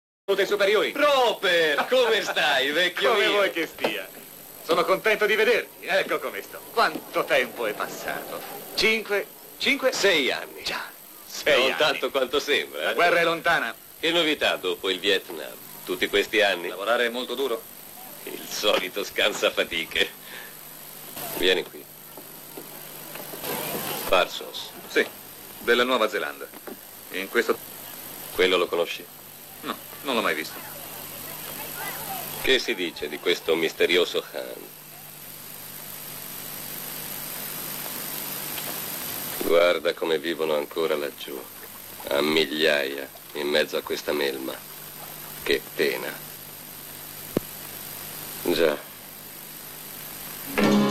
nel film "I 3 dell'Operazione Drago", in cui doppia Jim Kelly.